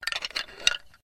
the-bone-of-the-skeleton-moves.ogg